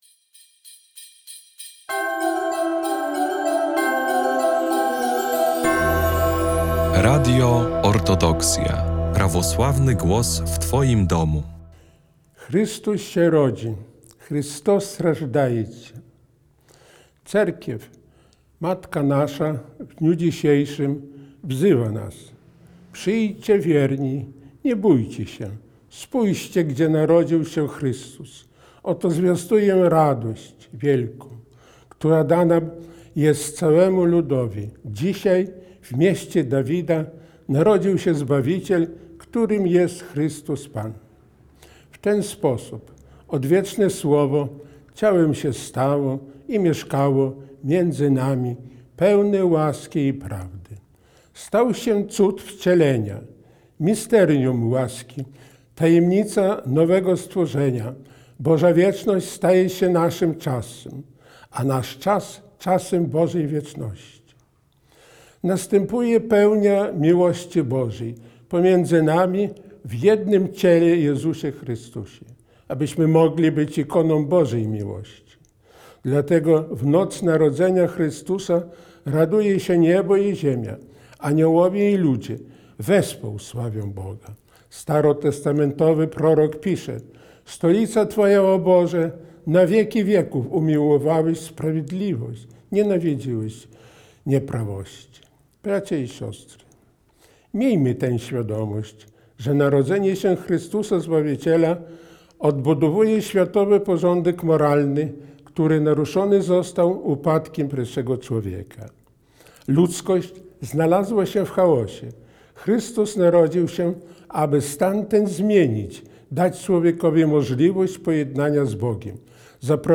Orędzie Bożonarodzeniowe Jego Eminencji Metropolity Sawy 2026